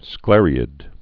(sklĕrē-ĭd)